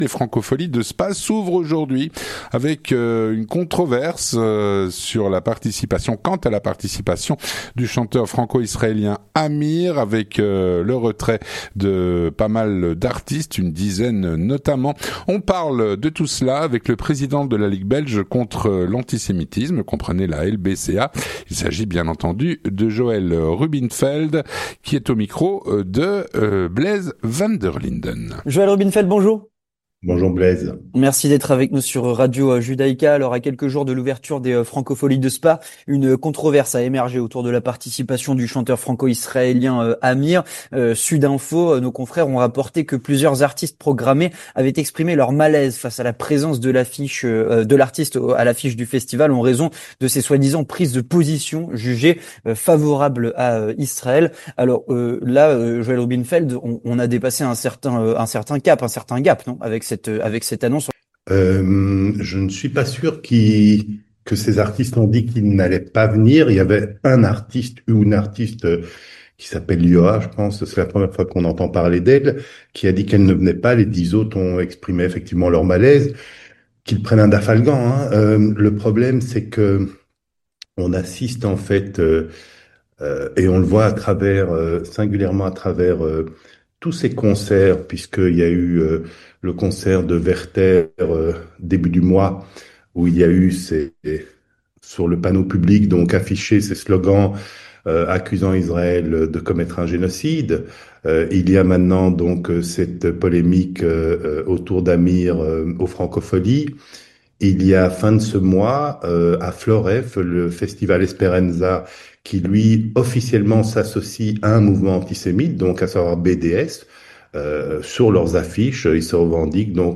Il est au micro